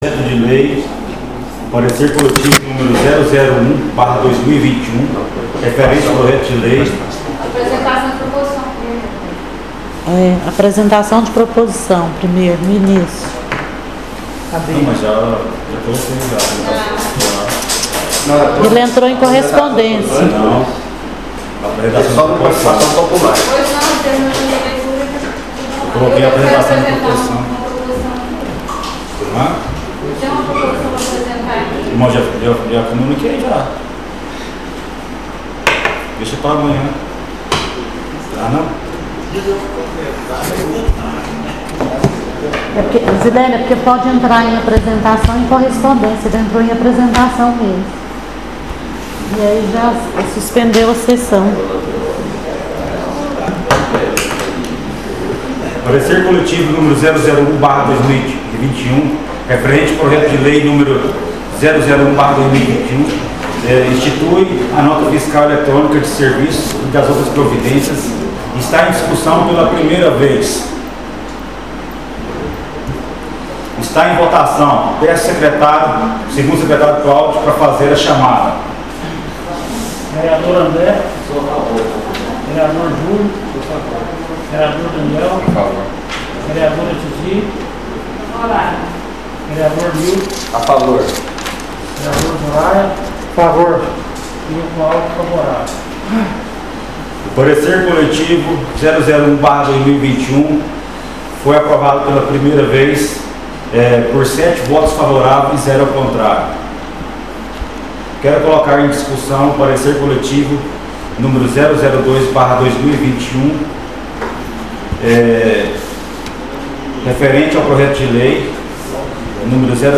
SESSÃO ORDINÁRIA DIA 17/02/2021 PARTE 2